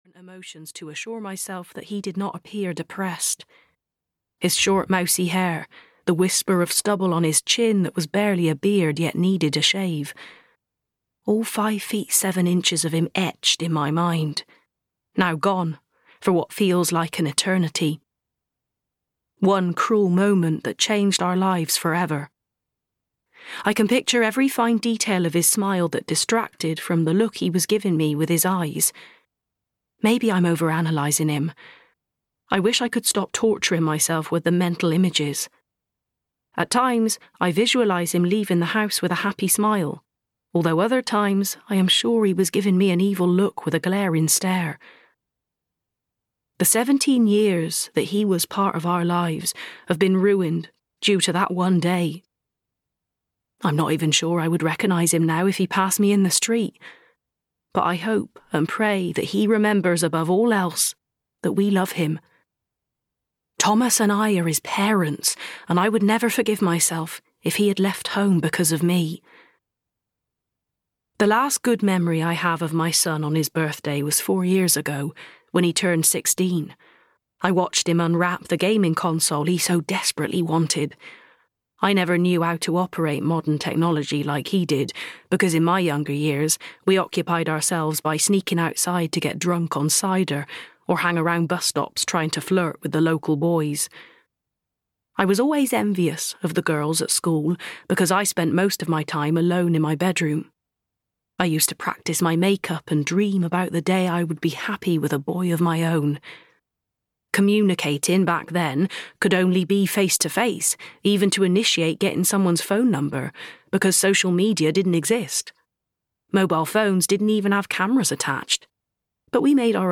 Mummy's Boy (EN) audiokniha
Ukázka z knihy